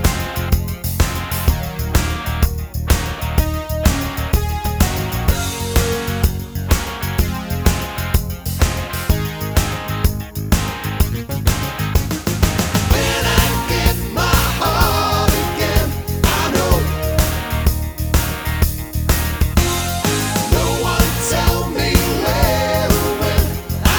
Four Semitones Down Pop (1970s) 4:42 Buy £1.50